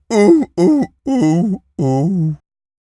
pgs/Assets/Audio/Animal_Impersonations/seal_walrus_death_slow_01.wav at master
seal_walrus_death_slow_01.wav